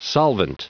Prononciation du mot solvent en anglais (fichier audio)
Prononciation du mot : solvent